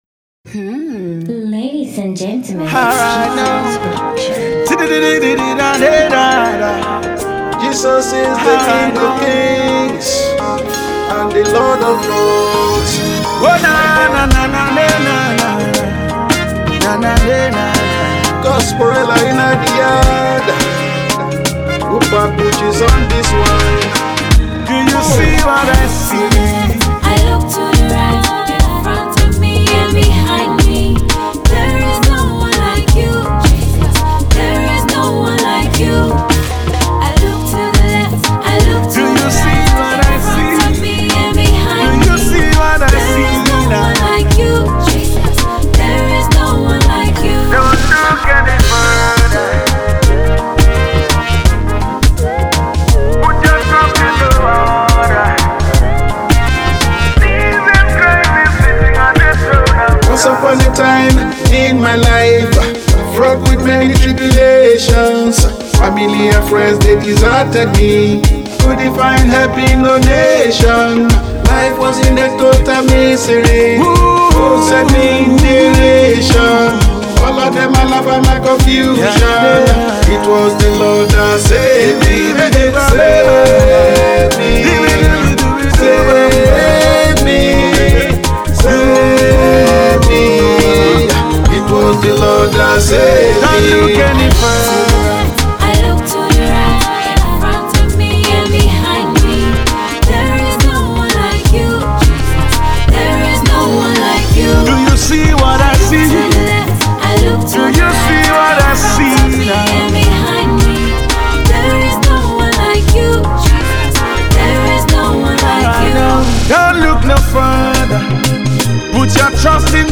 dance hall tune